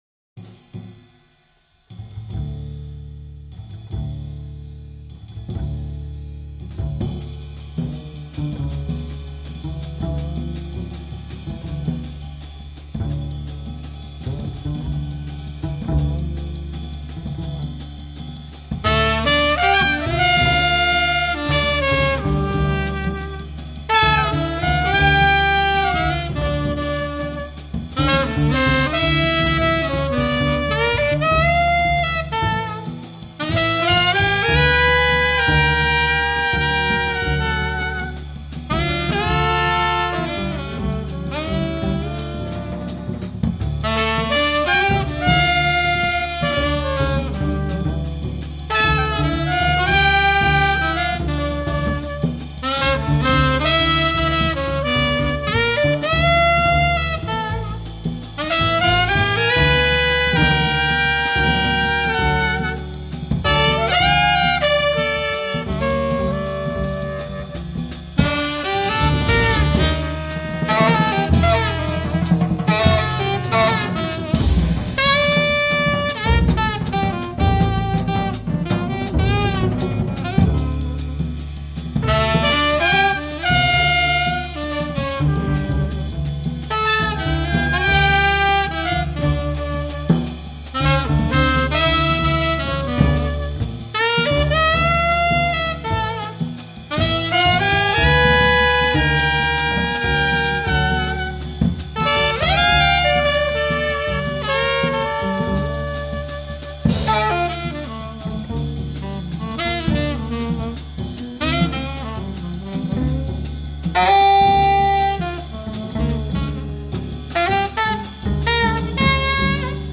موسیقی Jazz
موسیقی جَز